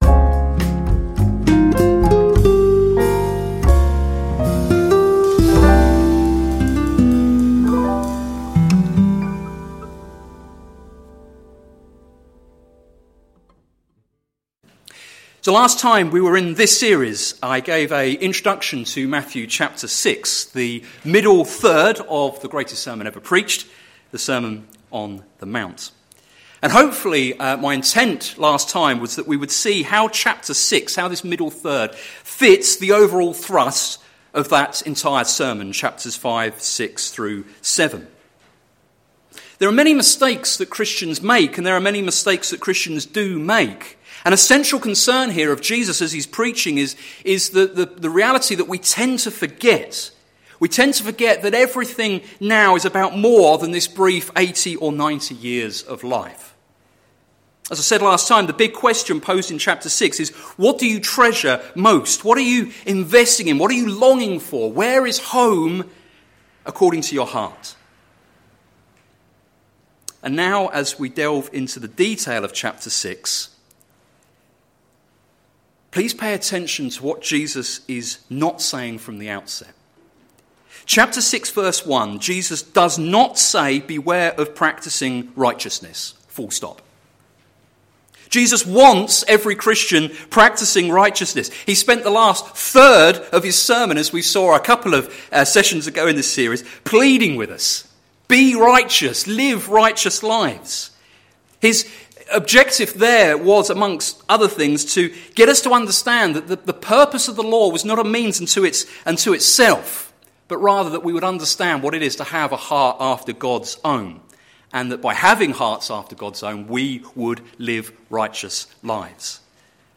Sermon Series - Things Jesus said - plfc (Pound Lane Free Church, Isleham, Cambridgeshire)